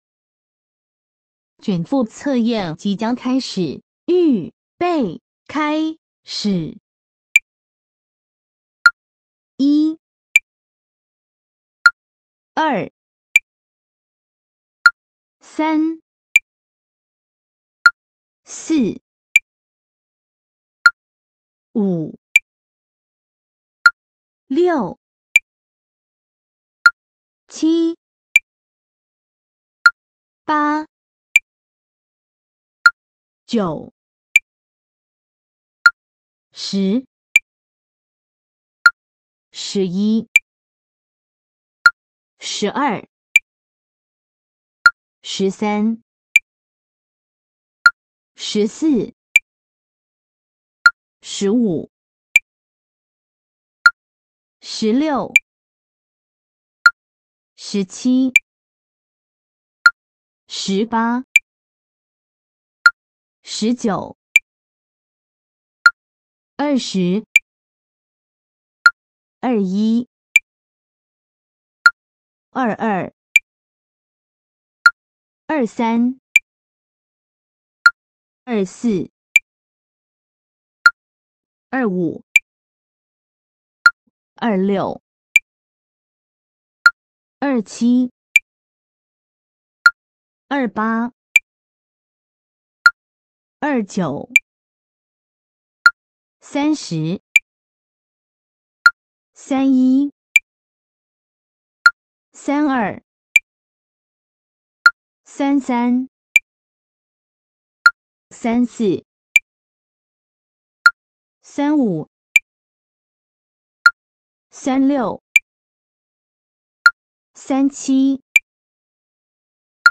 40 bpm 仰臥捲腹音訊檔.mp3